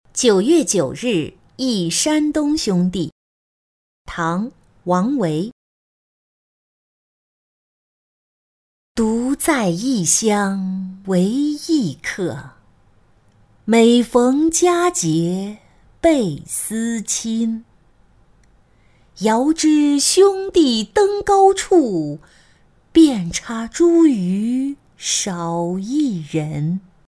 江州重别薛六柳八二员外-音频朗读